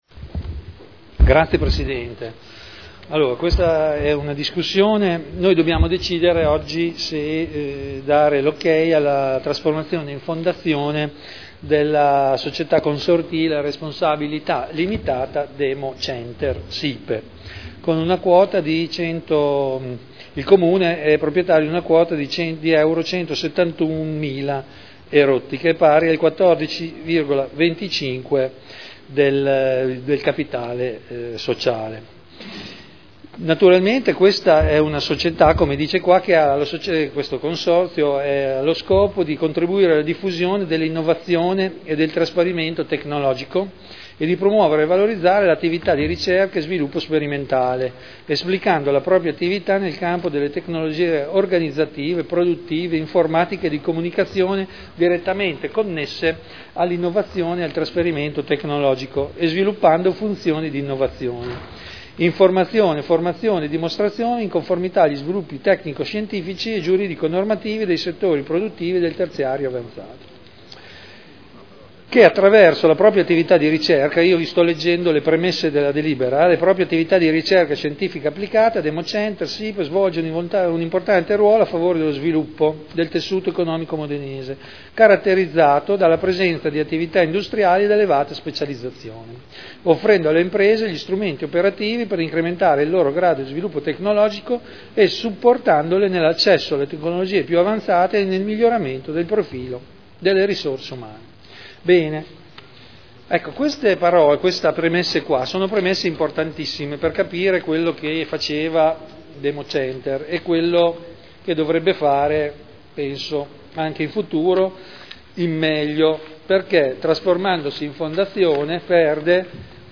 Seduta del 22/12/2011. Dibattito su proposta di deliberazione. Trasformazione di Democenter-Sipe da Società Consortile a Responsabilità Limitata a Fondazione – Approvazione dello statuto